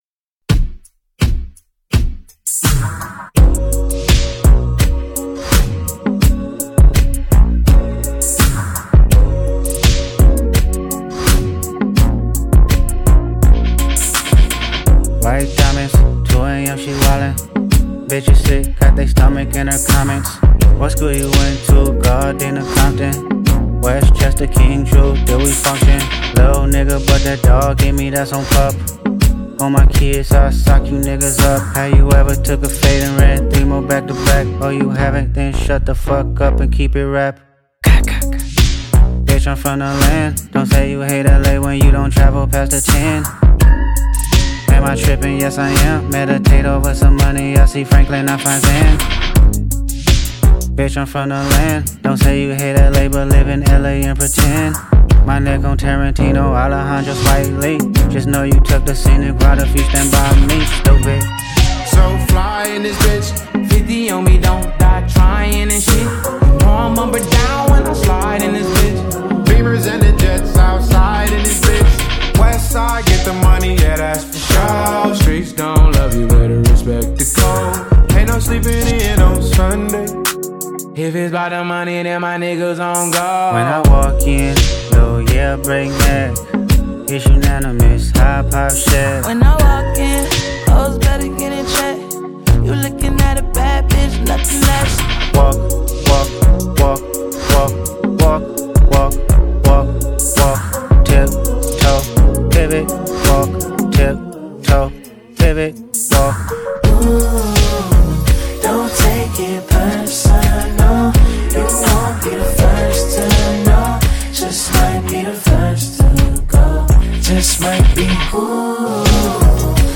With nice vocals and high instrumental equipments